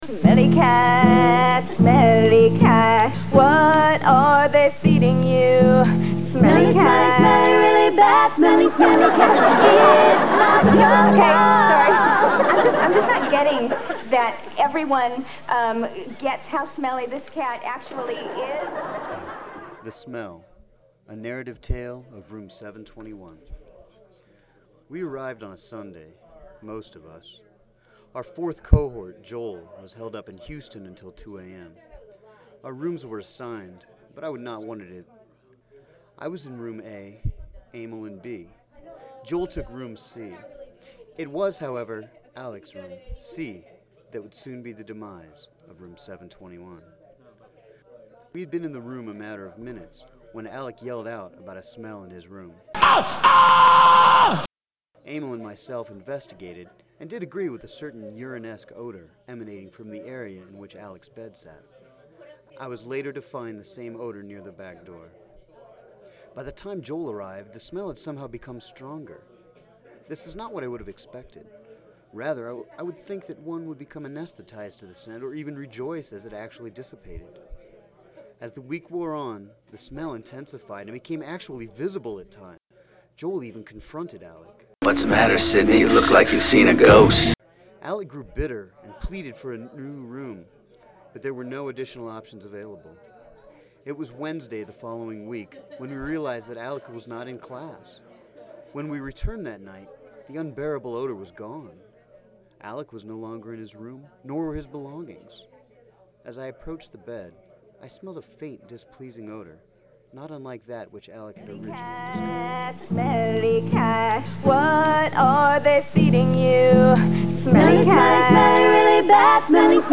Smelly Cat Podcast," a one-off audio file produced during a C&W workshop session, is an exercise that demonstrates the emerging promise of adapting podcasts to the academic conference environment.